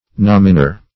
Nominor \Nom"i*nor`\, n.